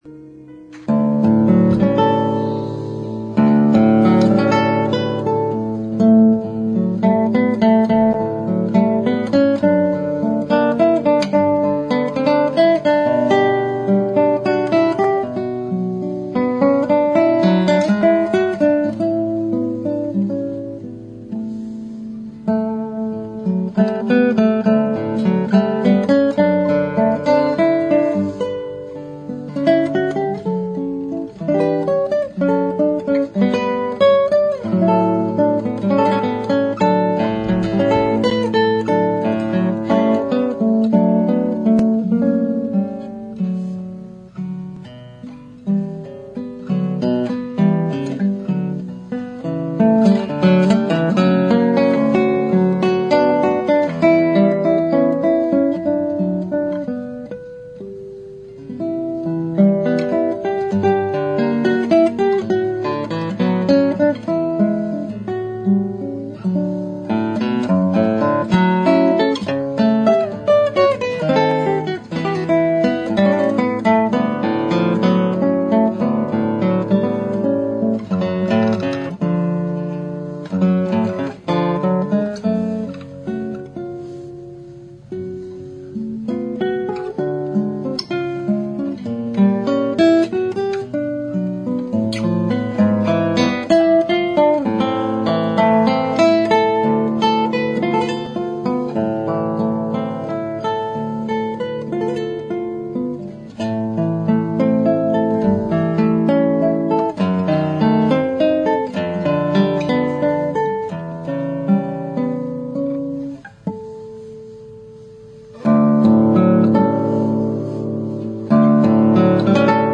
realizada en el Instituto Goethe